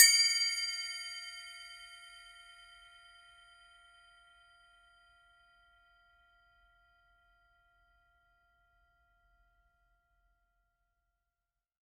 5" Cup Chime
5_cup_chime_edge.mp3